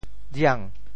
酿（釀） 部首拼音 部首 酉 总笔划 24 部外笔划 17 普通话 niàng 潮州发音 潮州 riang2 文 潮阳 riang2 文 澄海 riang2 文 揭阳 riang2 文 饶平 riang2 文 汕头 riang2 文 中文解释 酿 <动> (形声。